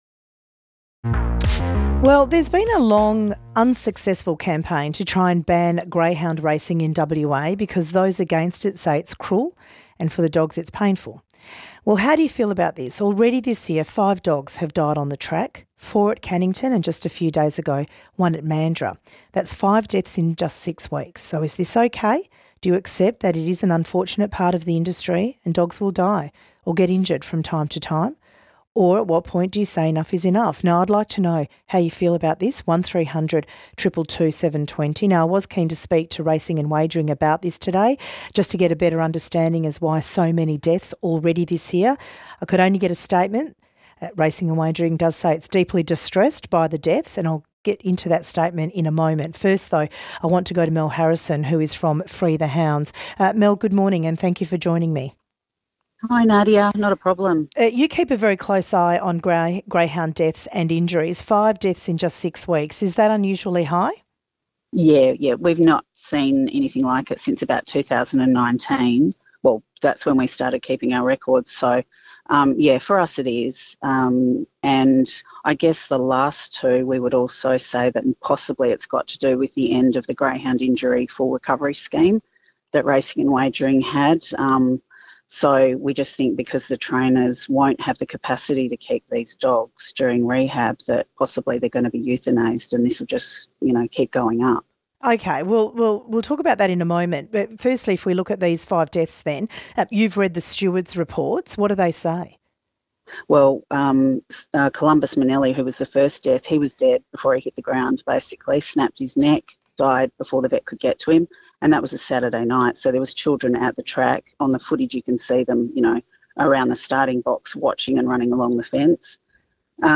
From ABC Radio Perth, 6 February 2024: The Mayor of Canning, Patrick Hall, reignites debate over greyhound racing in Western Australia.